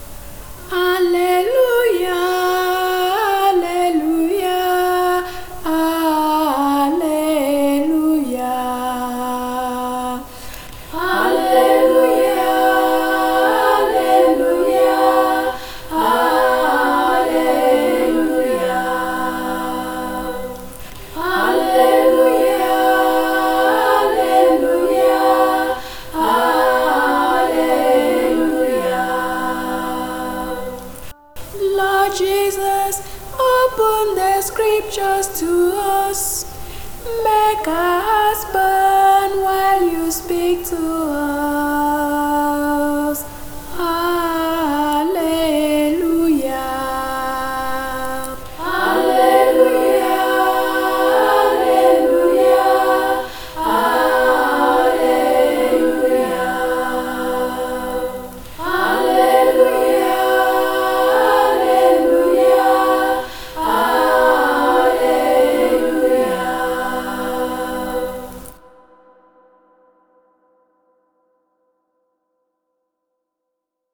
Alleluia Acclamation – Luke 24:32
db5af-alleluia_acclamation_for_third_sunday_of_easter_year_a.mp3